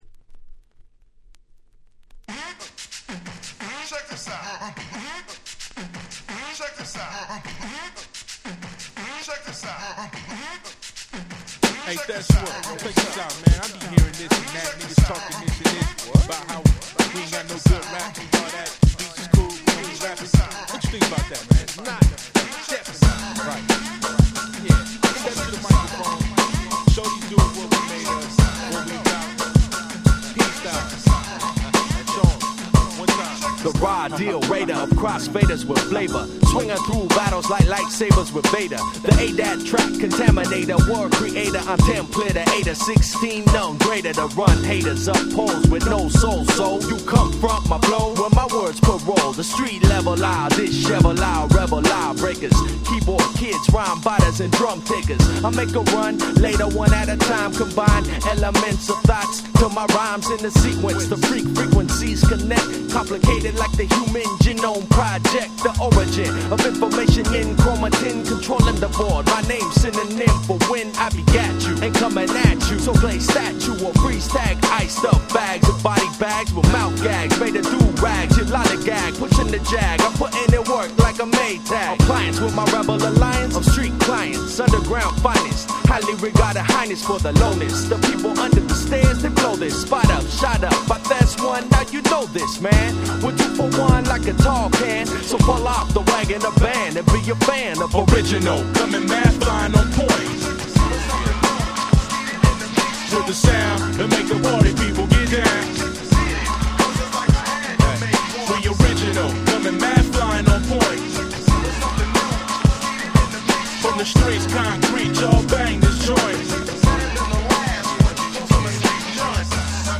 02' Very Nice Underground Hip Hop !!
オリジナルもバッチリですがよりJazzyに仕上がったRemixも最高。